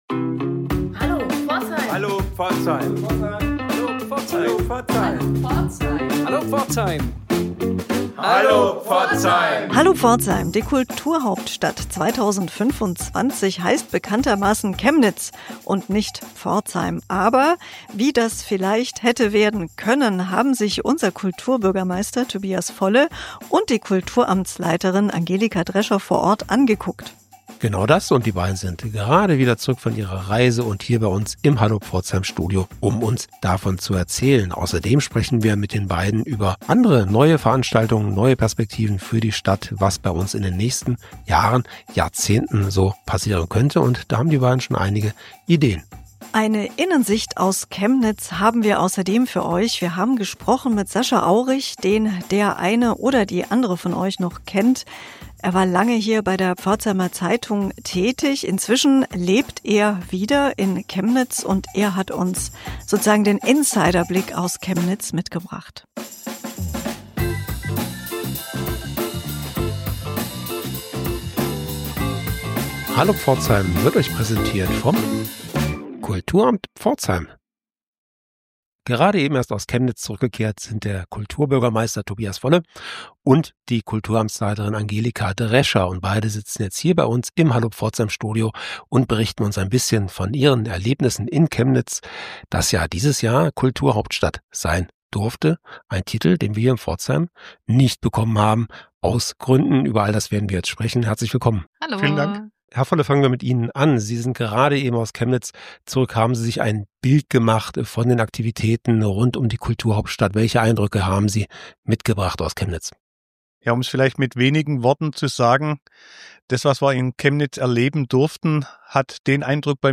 Höre jeden Monat neue Interviews und Reportagen zu aktuellen kulturellen Themen mit Kunst- und Kulturschaffenden und anderen Akteuren.